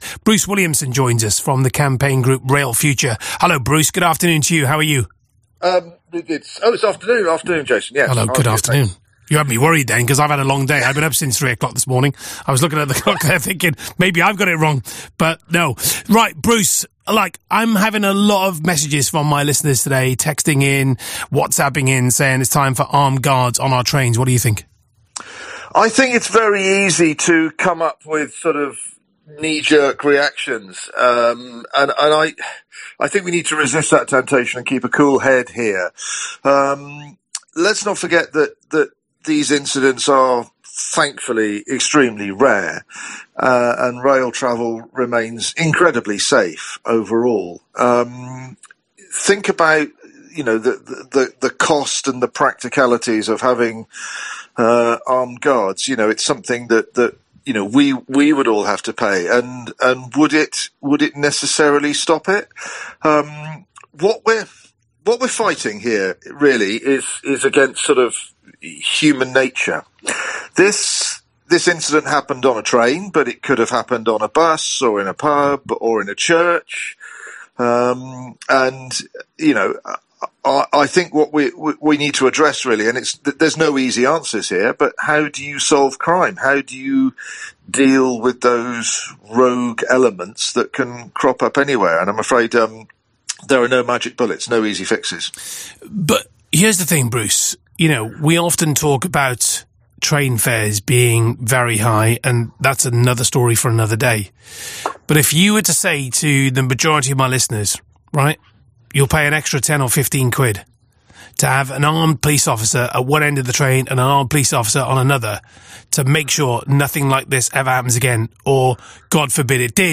BBC Radio Wales hosts a discussion on the topic of the recent stabbing incident on an LNER service. What is the way forward?